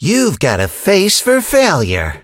kit_kill_vo_04.ogg